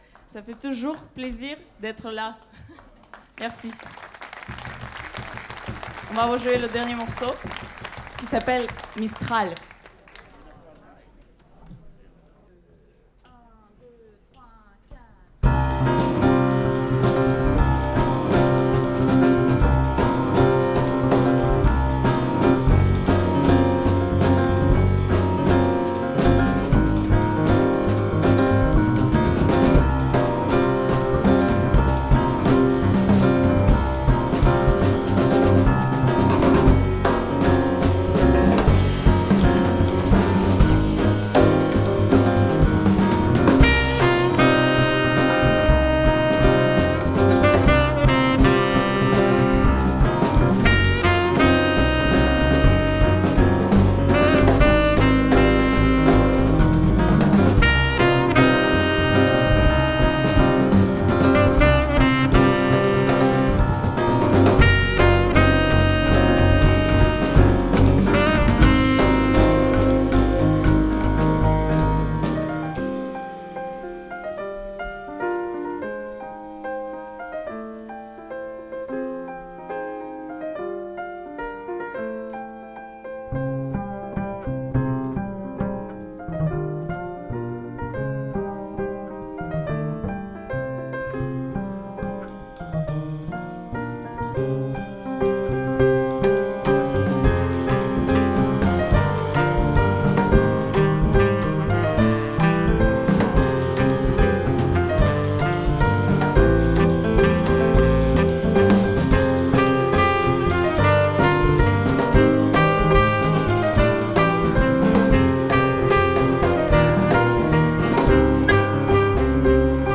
saxophone
batterie
contrebasse